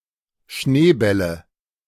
A Schneeball (German: [ˈʃneːˌbal] ) or Schneeballen (plural: Schneebälle (German: [ˈʃneːˌbɛlə]
De-Schneebälle.ogg.mp3